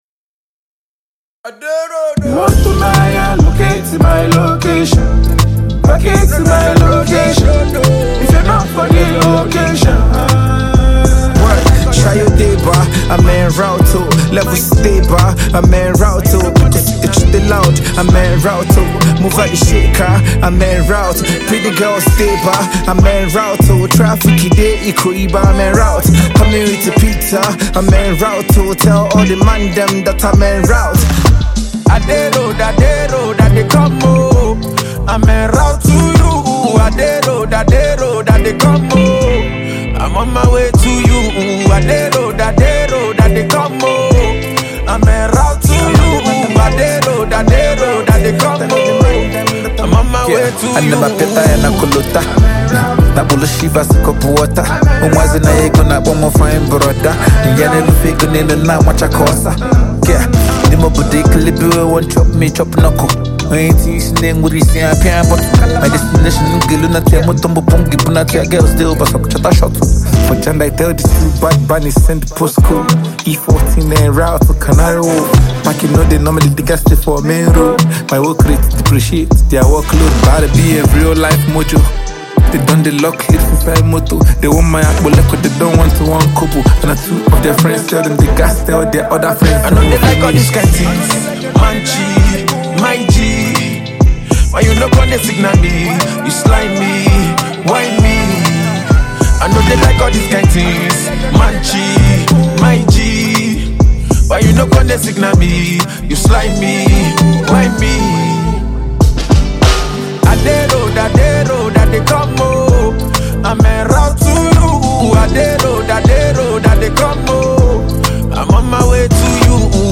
Nigerian indigenous rapper
Nigerian singing duo from Port Harcourt